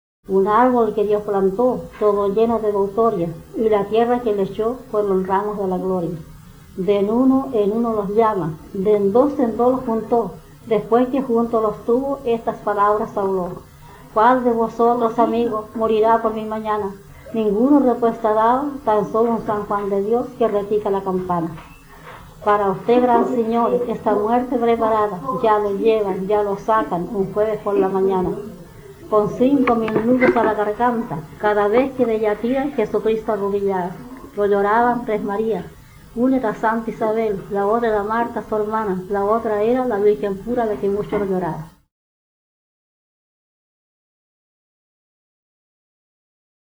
Romance en forma de oración que trata el tema tradicional de "las santas mujeres".
Folklore
Romance
Tradición oral